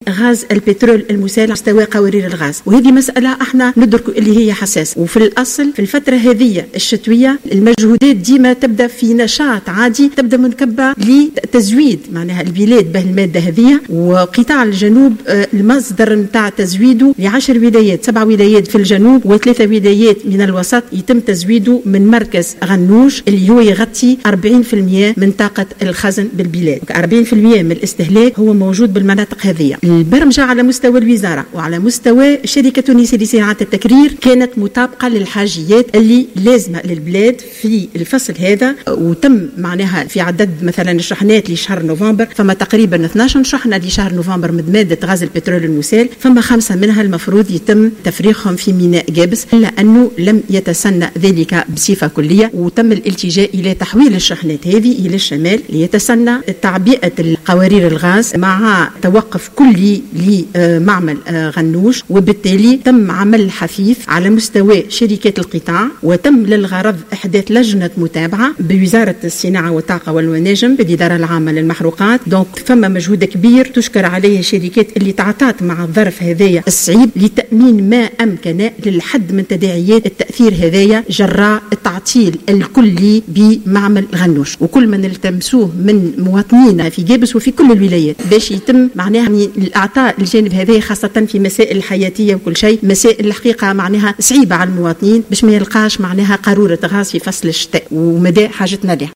و أضافت الصغير، في إجابتها على اسئلة النواب خلال مناقشة ميزانية وزارة الصناعة والطاقة والمناجم لسنة 2021 أن محطة غنوش تزود 10 ولايات وهي توفر 40 بالمائة من الطلبات على قوارير الغاز.